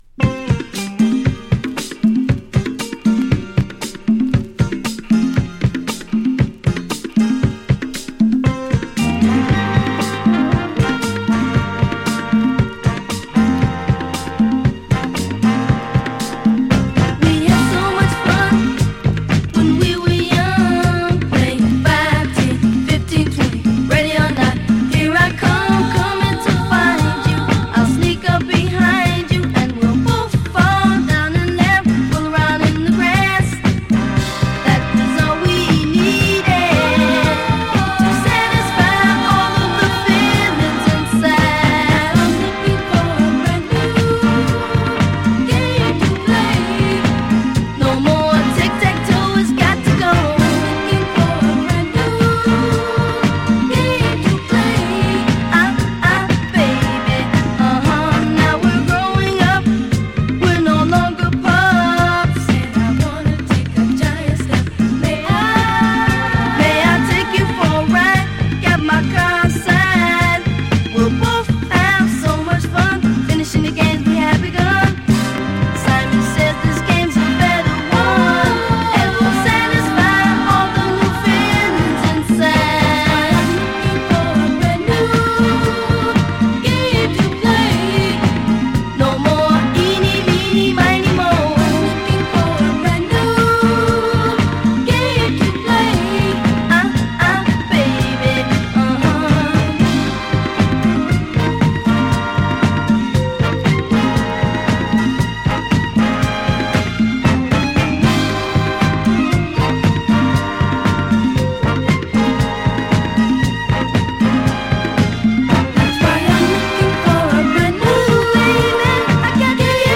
Free Soul, Disco us
ファミリー・グループと思しきUSの8人組
パーカションを入れたバッキング、若かりし日のM.ジャクソンを彷彿させるメロウな歌声もステキな一曲です。